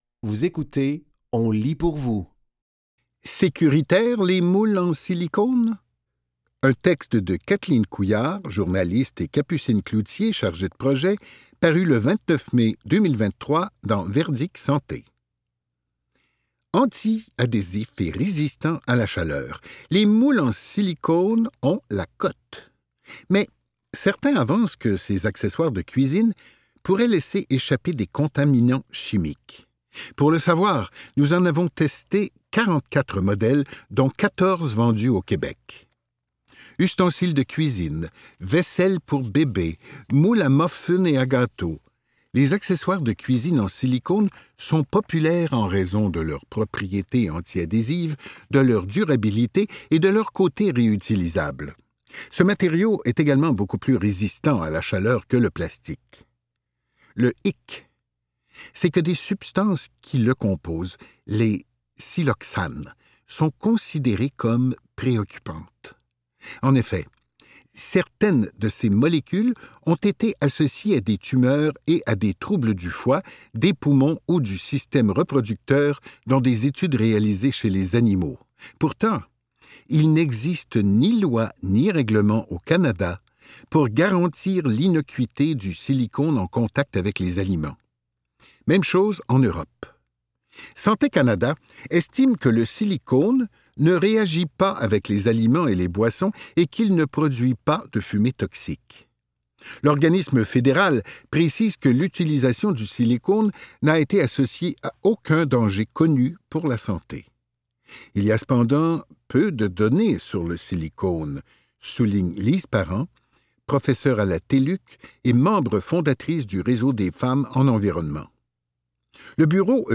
Dans cet épisode de On lit pour vous, nous vous offrons une sélection de textes tirés des médias suivants : Verdict Santé et Fugues.